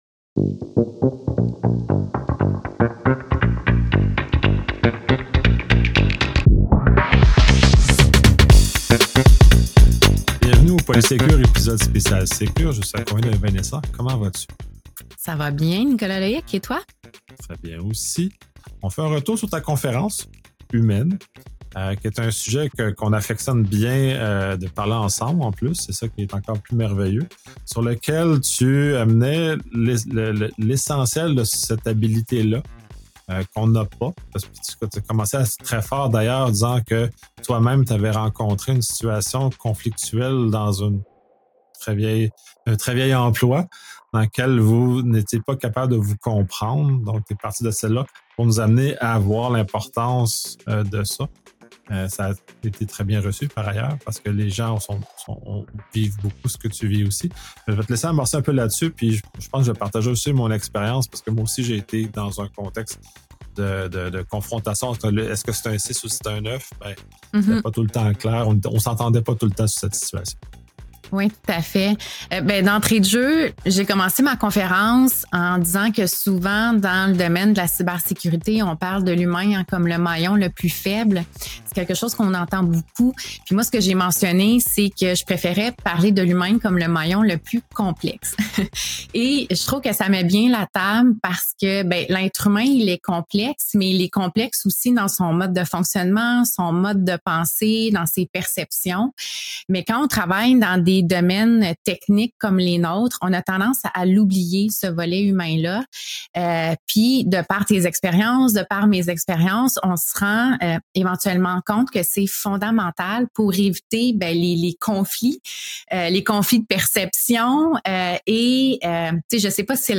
Podcast francophone sur la cybersécurité.